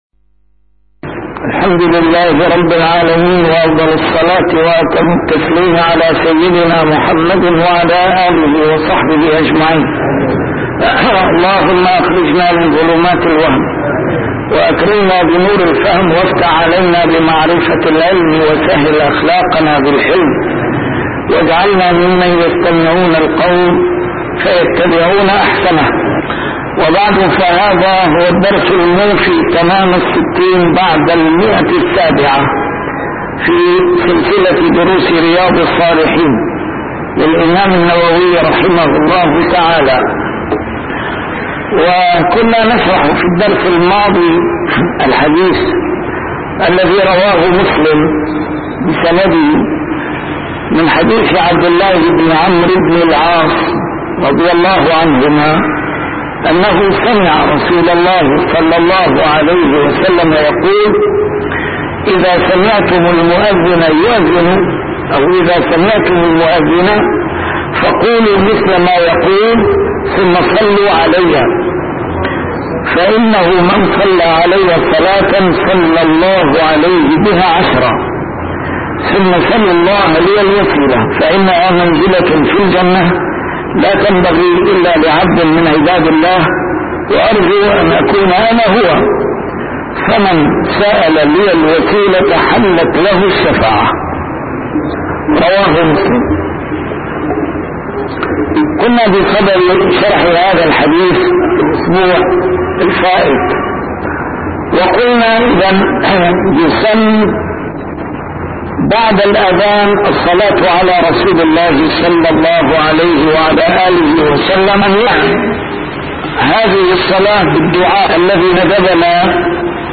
A MARTYR SCHOLAR: IMAM MUHAMMAD SAEED RAMADAN AL-BOUTI - الدروس العلمية - شرح كتاب رياض الصالحين - 760- شرح رياض الصالحين: فضل الأذان